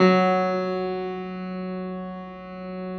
53g-pno08-F1.wav